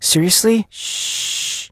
Unused voice line of the Brawler Sandy, played after defeating an enemy Brawler.
File File history File usage Metadata BS_sandy_kill_vo_03.ogg  (Ogg Vorbis sound file, length 1.7 s, 69 kbps) Summary This file is an audio rip from a(n) Android game.